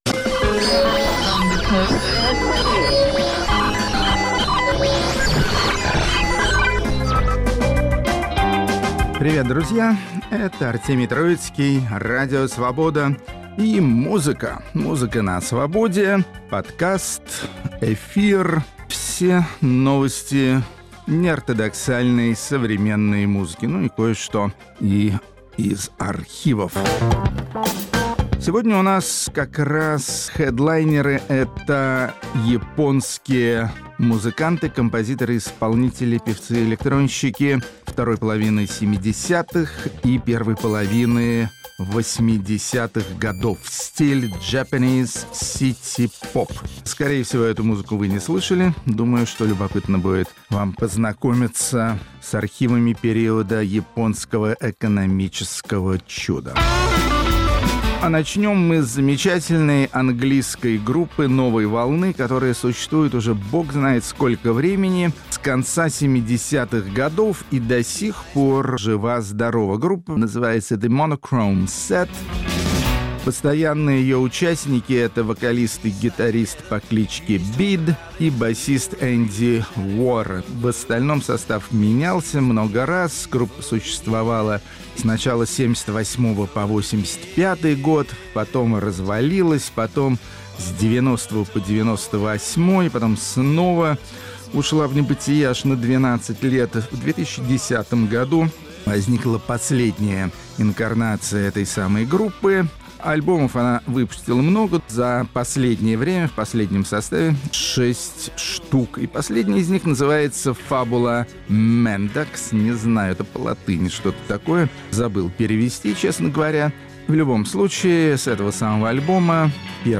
Хедлайнеры подкаста и радиопрограммы "Музыка на Свободе" – японские исполнители стиля city pop.